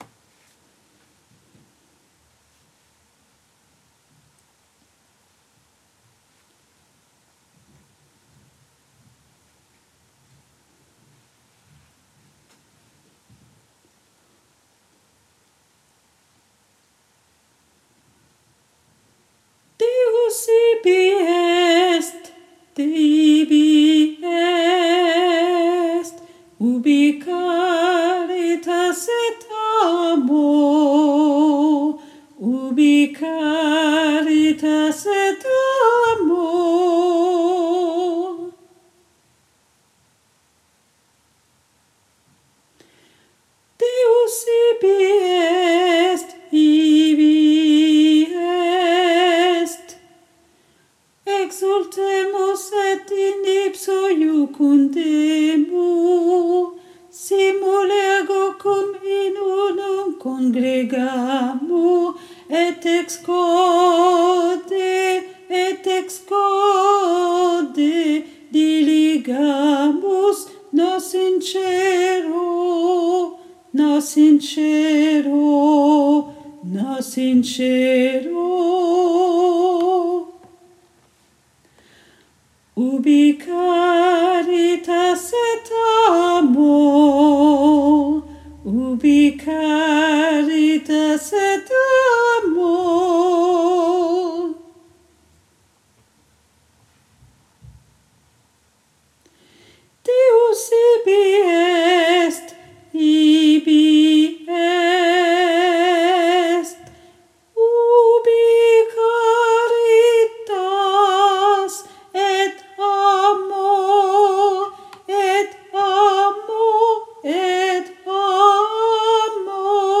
MP3 versions chantées
Basse